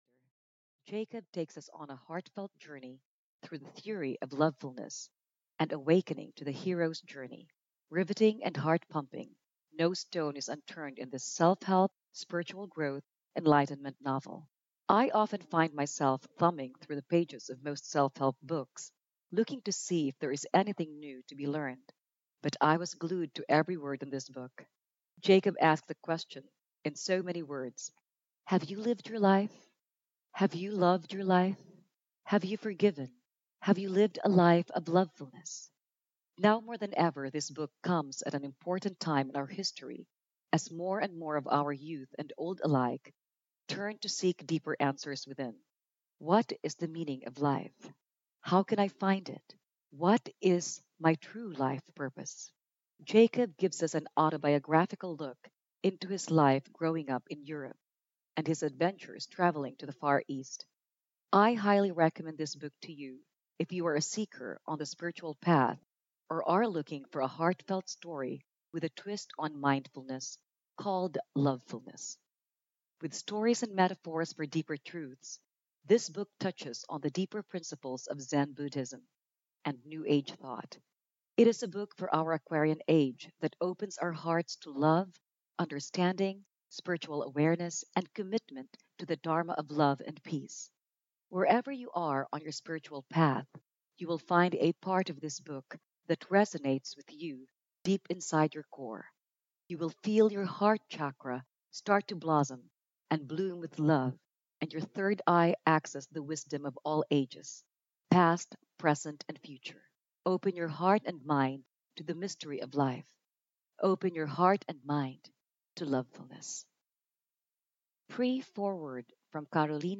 Audio knihaThe Mystery of LifeYou are the Light, and that's indestructible truth
Ukázka z knihy